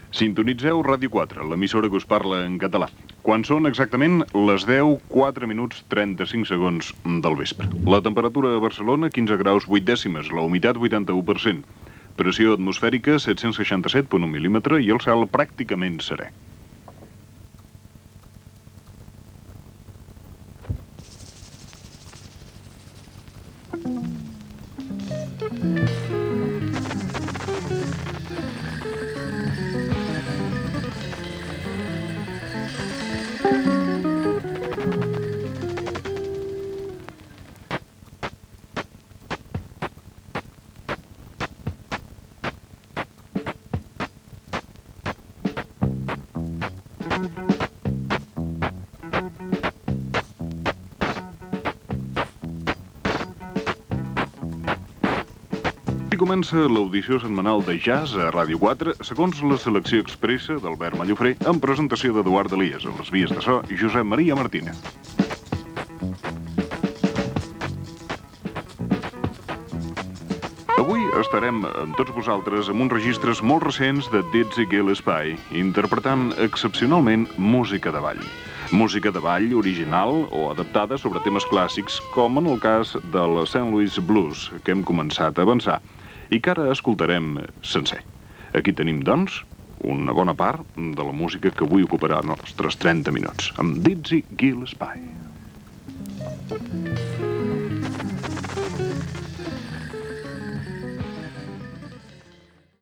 Identificació, hora estat del temps i inici del programa dedicat al jazz.
Musical
FM